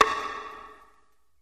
CC - Perc.WAV